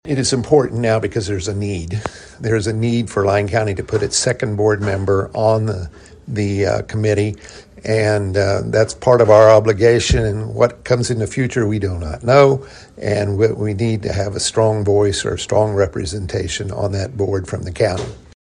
Commission Chair Rollie Martin says this was an important move for the county.